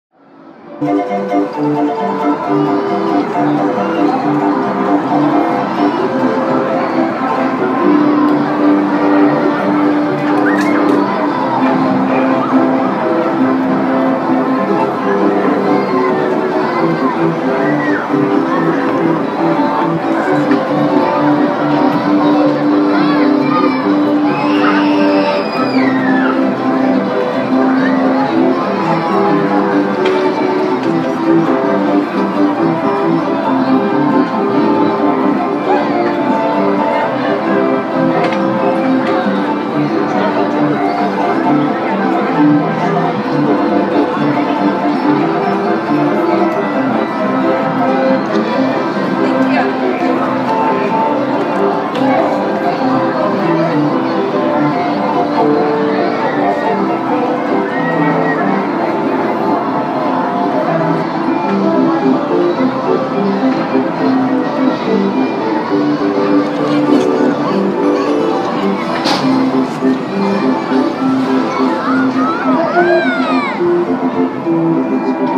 Звуки карнавала
Атмосфера карнавала, детский шум